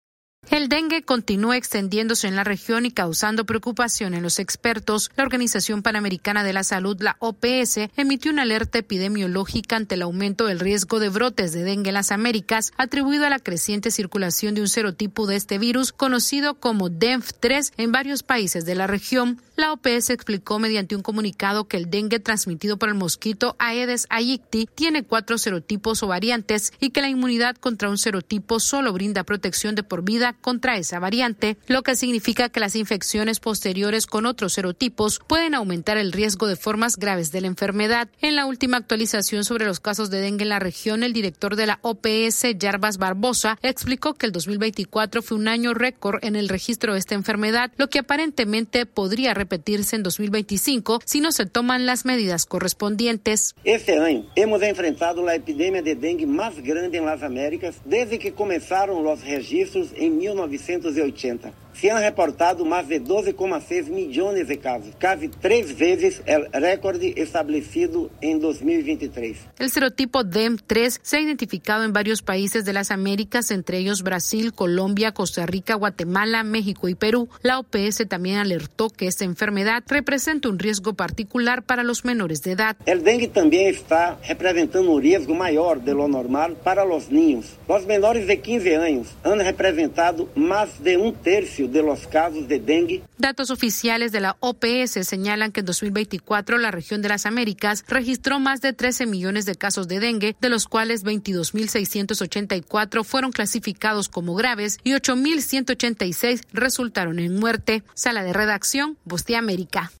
AudioNoticias
La Organización Panamericana de la Salud alertó sobre un mayor riesgo de brotes de dengue en las Américas debido a la circulación de las variantes DENV -3 en la región. Esta es una actualización de nuestra Sala de Redacción.